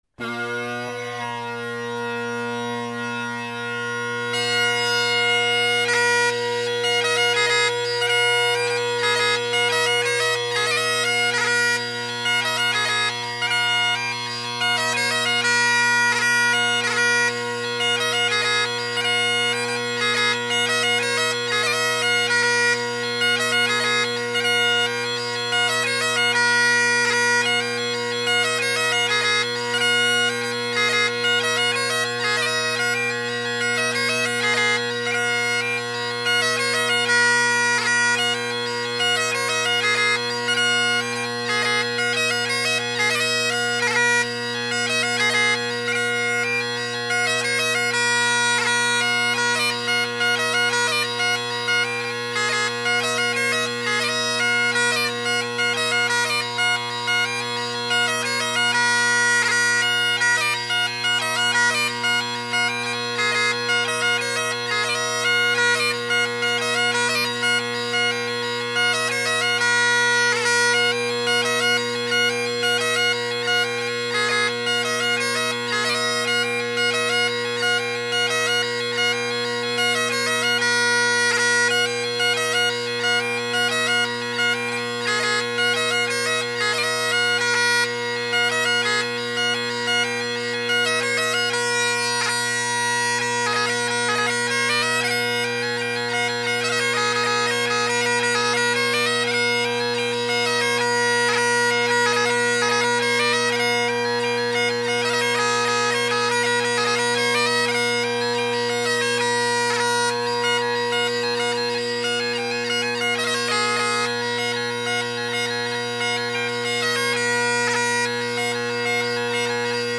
Great Highland Bagpipe Solo